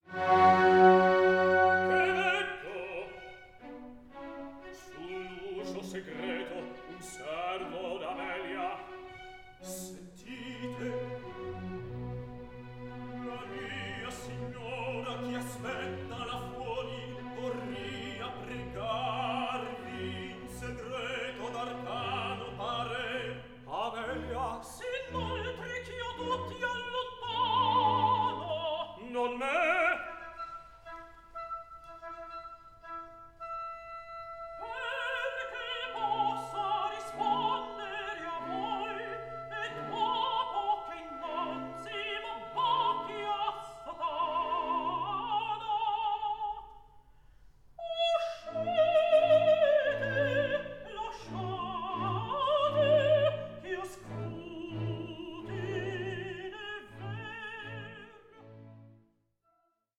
resulting in a performance that is lively and balanced.